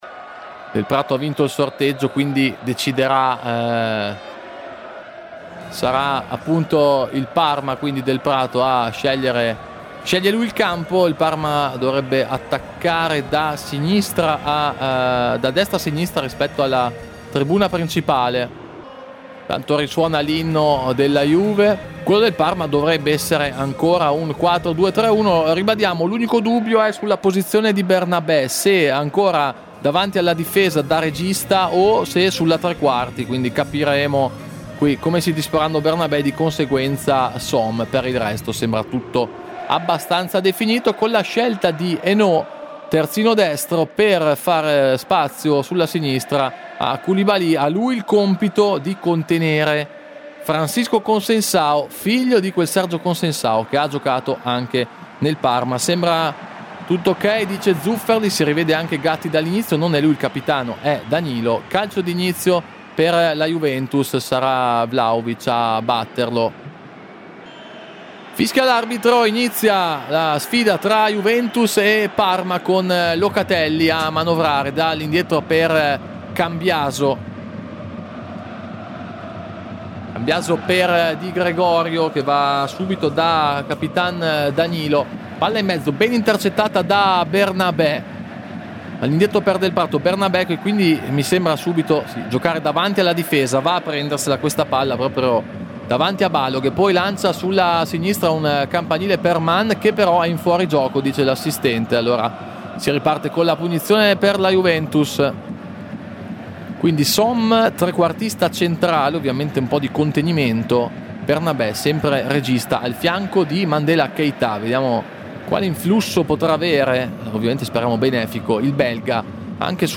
Radiocronache Parma Calcio Juventus - Parma 1° tempo - 30 ottobre 2024 Oct 30 2024 | 00:49:21 1x 00:00 / 00:49:21 Subscribe Share RSS Feed Share Link Embed